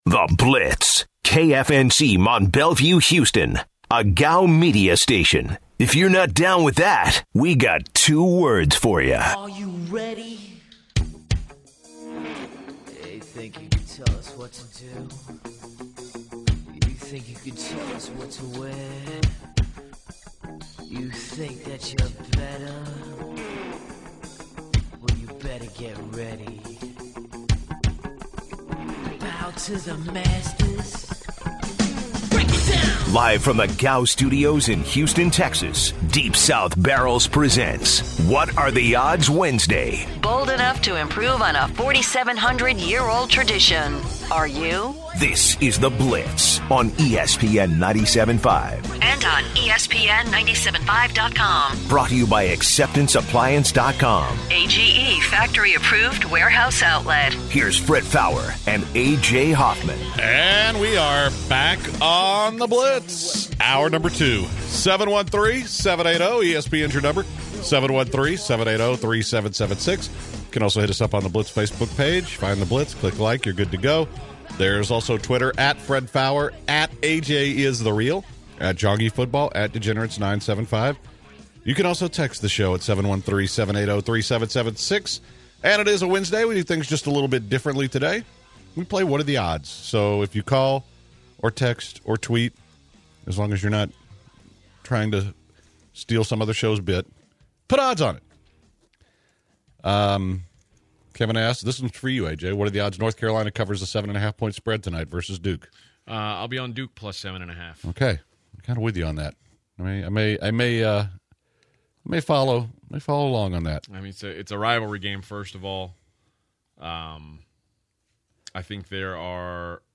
The guys open the second hour of The Blitz discussing the Apple iPhone situation with the U.S. Government.